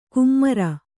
♪ kummara